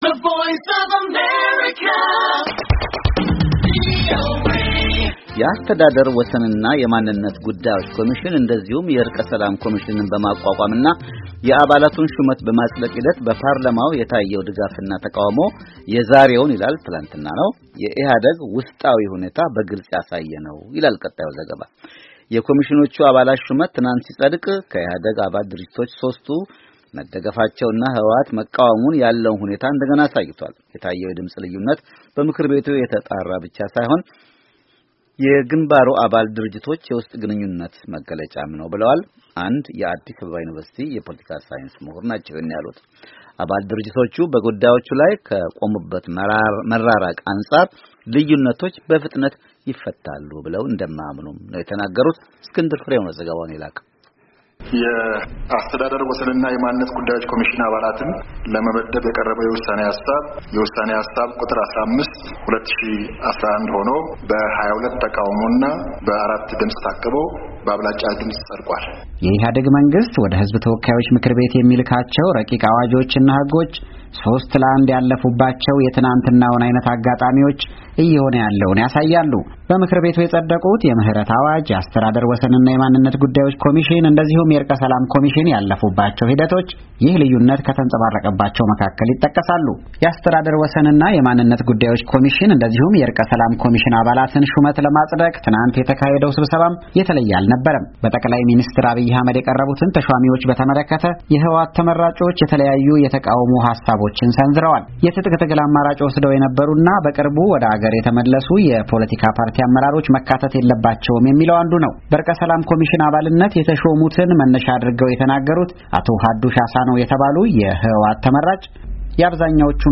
የአስተዳደር ወሰንና የማንነት ጉዳዮች ኮሚሽን እንደዚሁም የዕርቀ ሰላም ኮሚሽንን በማቋቋም እና የአባለቱን ሹመት በማፅደቅ ሂደት በፓርላማው የታየው ድጋፍና ተቀውሞ የዛሬውን የኢህአዴግ ውስጣዊ ሁኔታ በግልፅ ያሳየ ነው ይላል ቀጣዩ ዘገባ፡፡